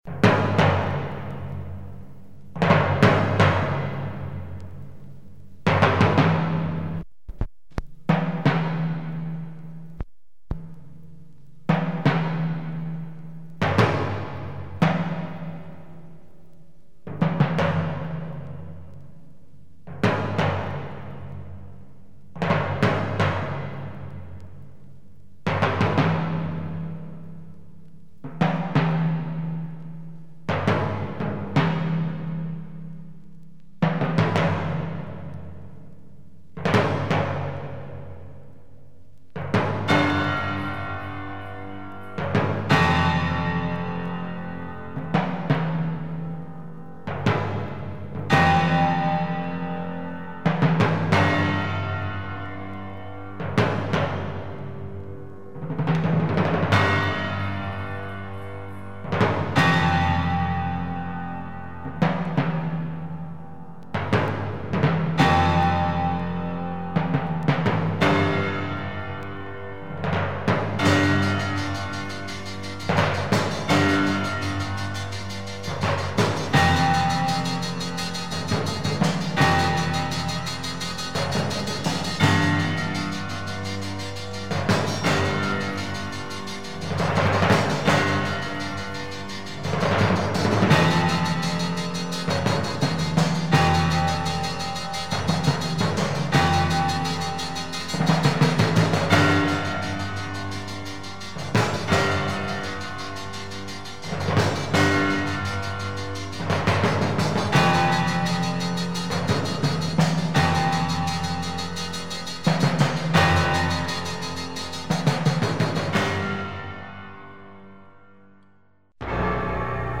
Heavy beats for hip hop
plus abstract sounds
Soundtracks